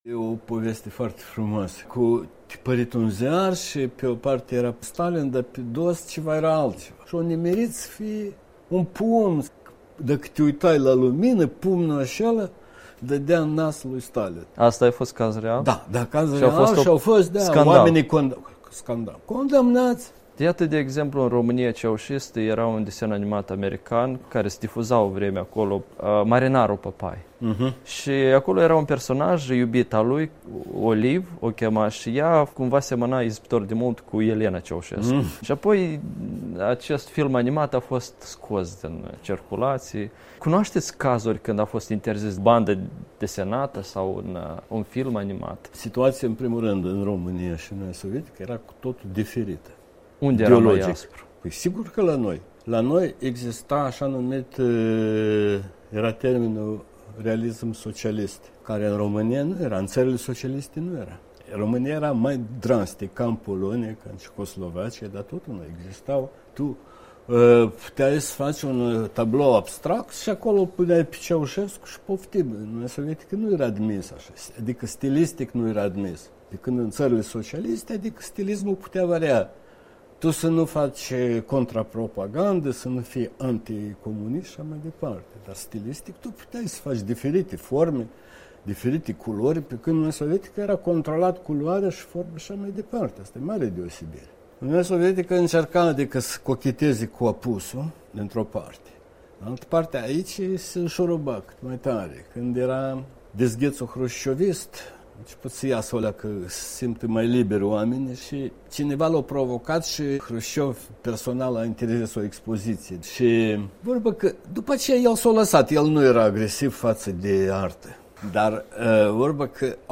Interviu cu Lică Sainciuc